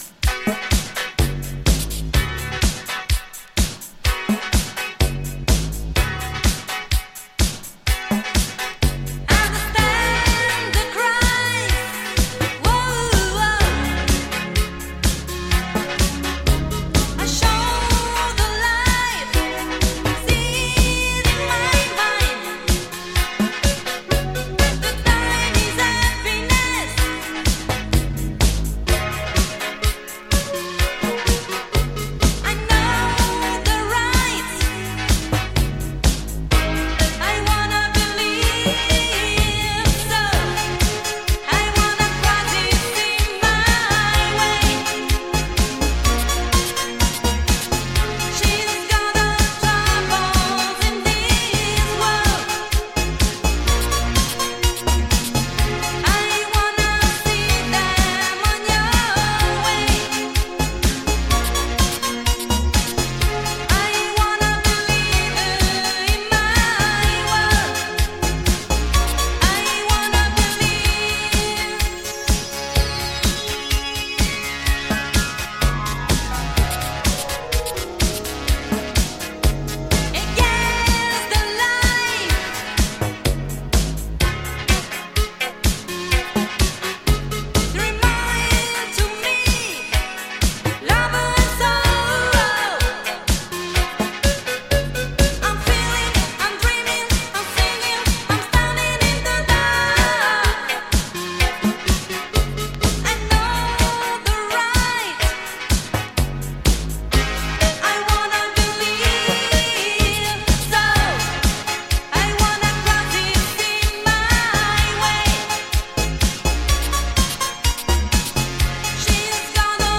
a prestigious Italo disco